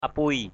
/a-pu̯əɪ/ ~ /a-puɪ/